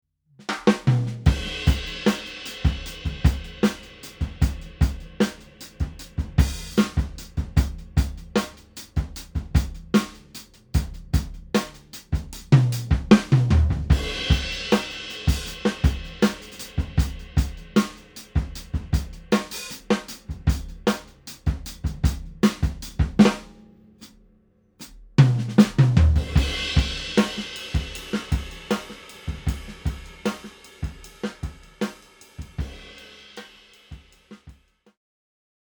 Hämmennyin, miten käyttökelpoista soundia sai pelkällä kahdella mikillä ja lisäksi siitä, että bassorumpukin tarttui aika hyvin ja stereokuvakin on olemassa.
Huonekorkeus oli tuskin kahta metriä enempää, eli sanalla sanoen todella pieni koppi.
Rumpuina nuo '81 Premierit, about samanikäinen Acrolite ja pelteinä K Constantinoplea (14" HH, 20" Medium ja 22" Medium Thin Low). Mikkietuasteena UA 2-610.
recorderman_r122_pukuhuone.mp3